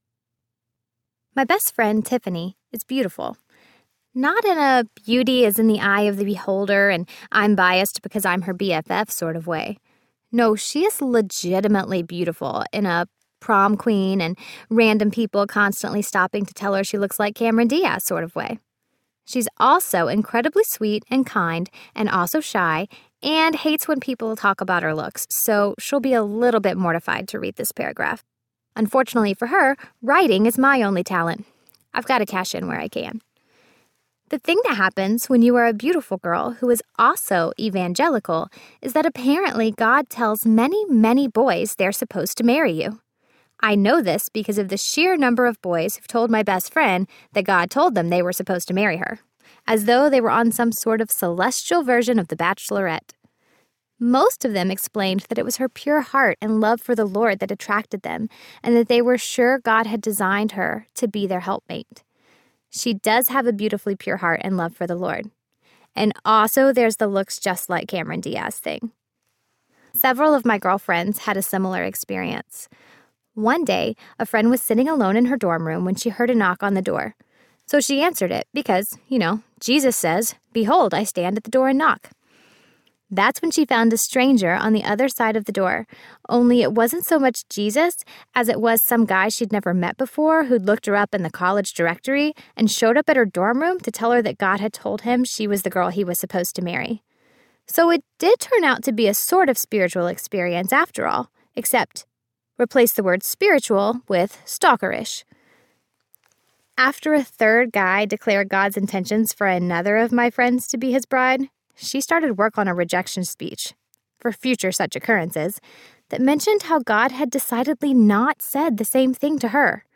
In Bloom Audiobook